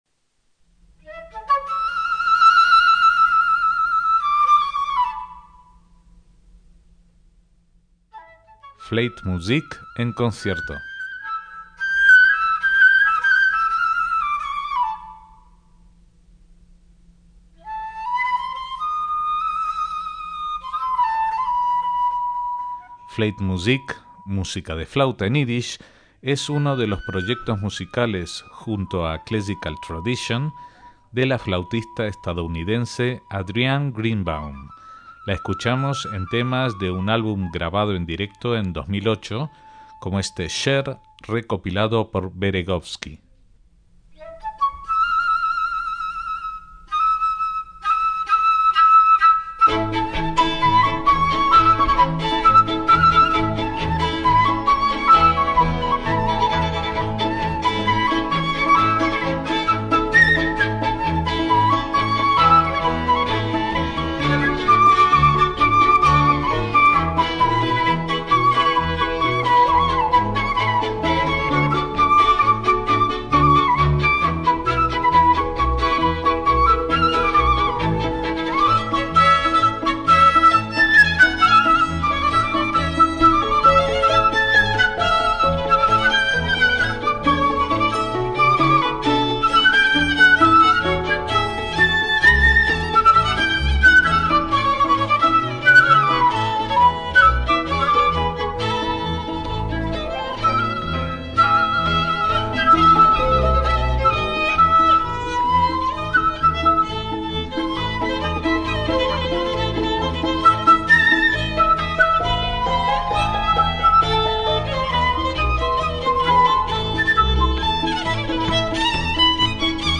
un conjunto con flauta, violín, cimbalom y bajo